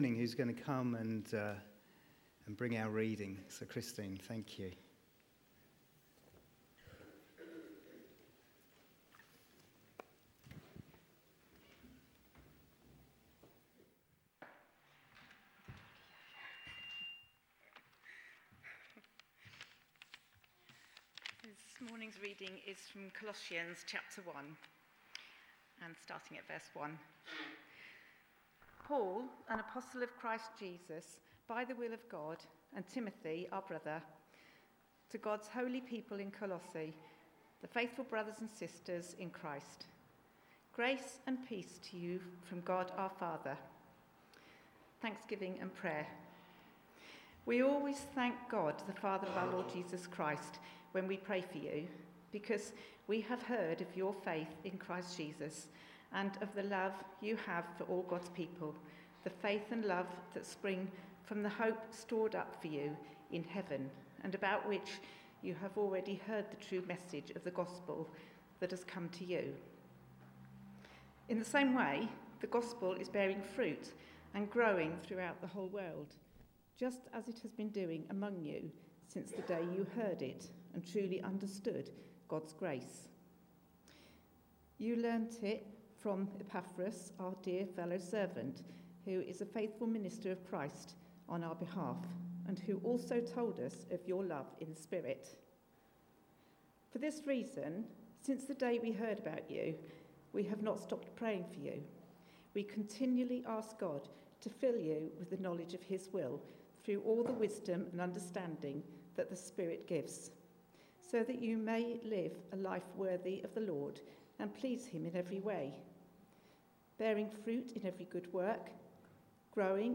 A message from the series "Jesus is Enough."